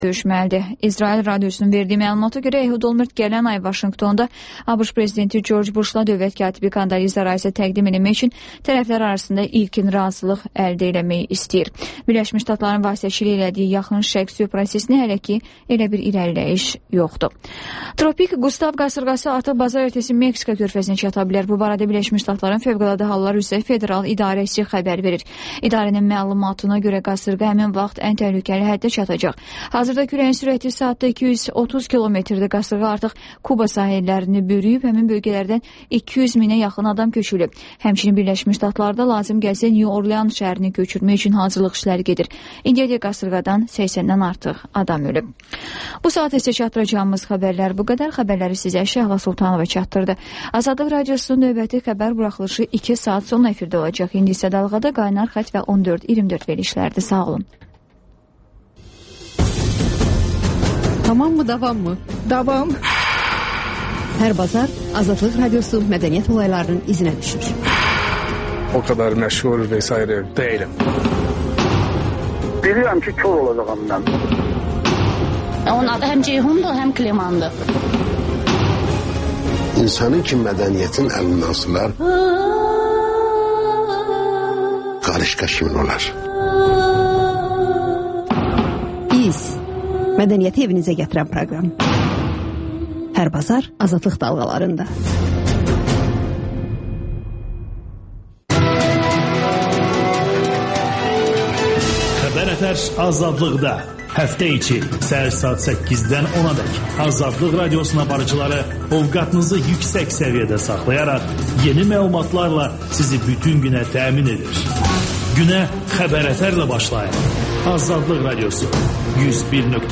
Dinləyici şikayətləri əsasında hazırlanmış veriliş (Təkrar)